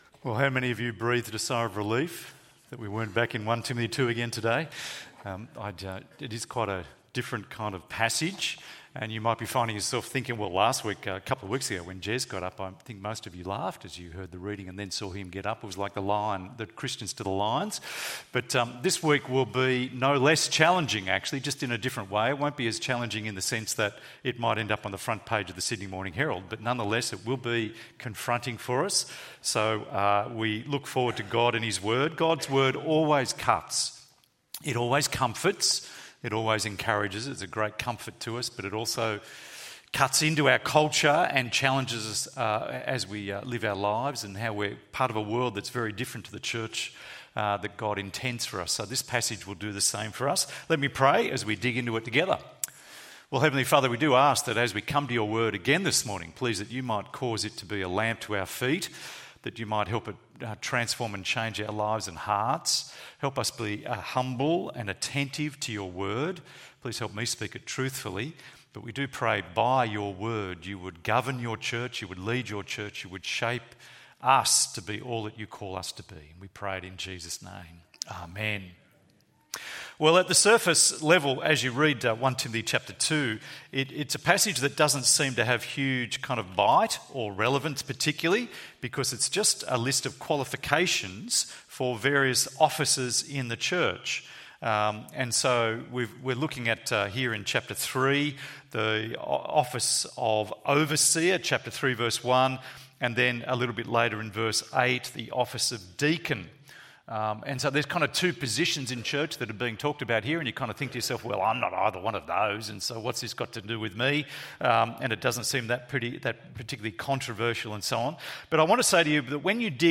Respectable Leaders ~ EV Church Sermons Podcast